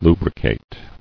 [lu·bri·cate]